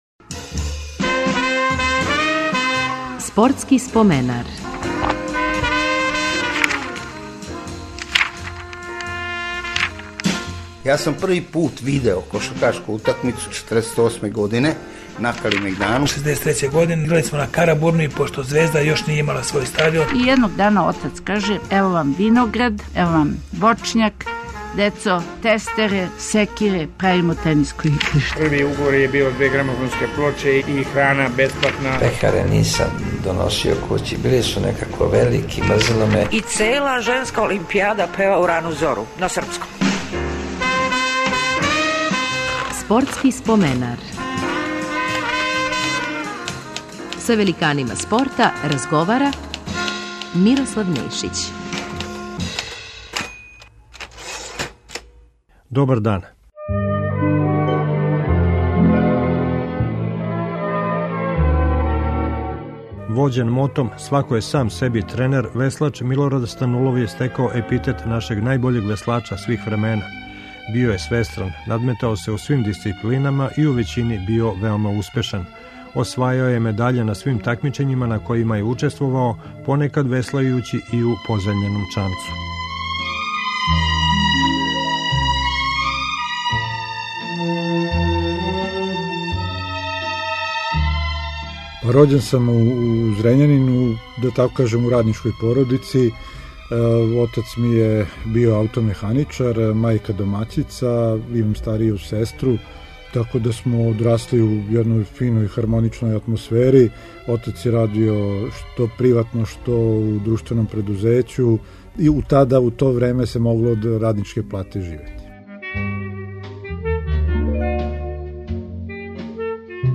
Гост емисије ће бити наш најбољи веслач свих времена Милорад Станулов.